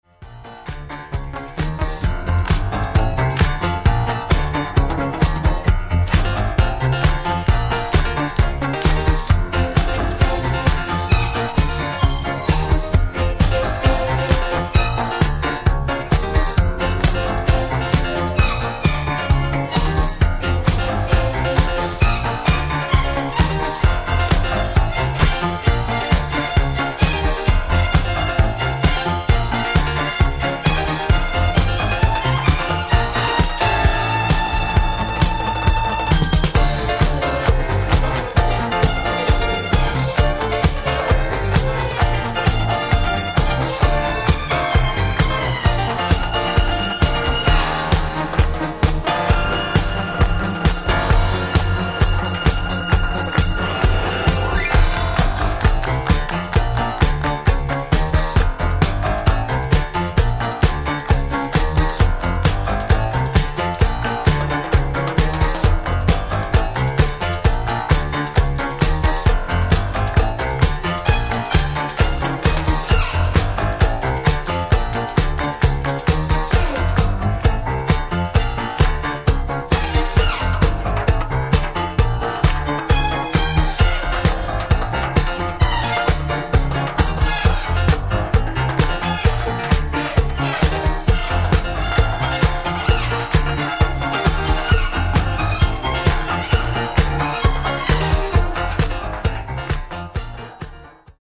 主に70sディスコ・ブギー路線のレア楽曲を捌いたエディット集となっています。
いずれもポジティヴなエネルギーで溢れた内容です！